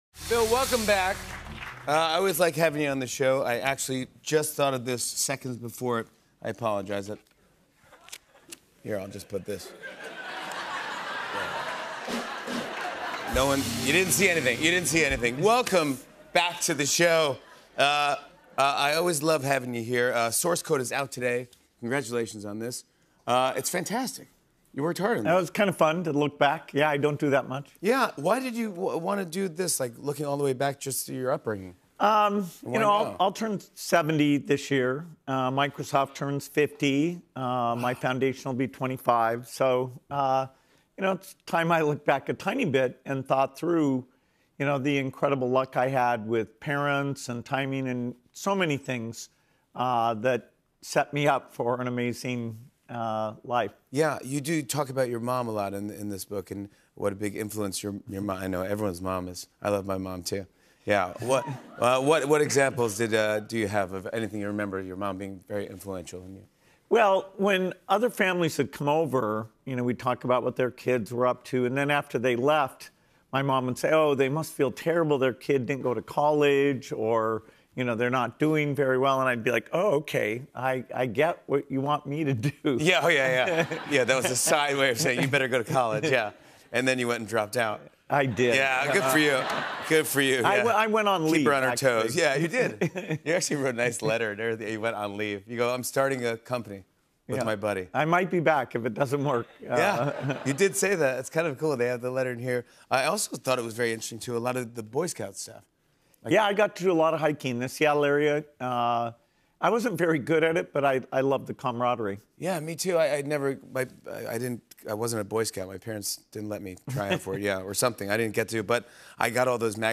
Я протестировал распознавание английского интервью с помощью ИИ — результаты на 26 февраля 2026 года (Whisper BASE, ~11 минут аудио)